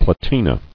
[pla·ti·na]